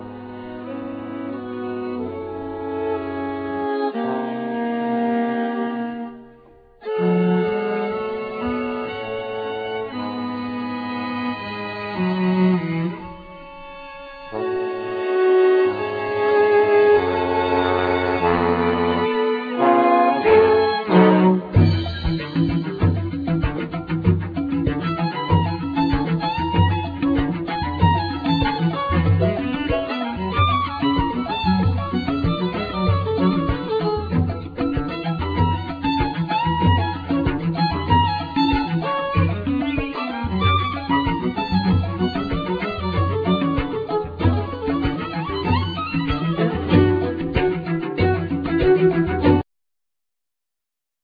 Percussions
Tuba
Violin
Viola
Cello